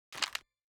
sprayer_take_oneshot_001.wav